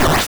bfxr_EnemyBoom.wav